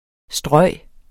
Udtale [ ˈsdʁʌjˀ ]